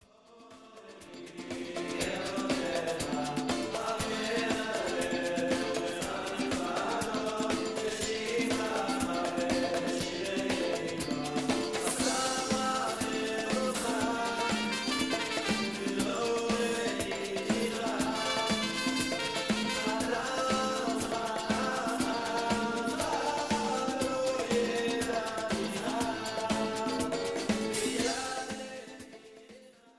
offers a stunning array of tight arrangements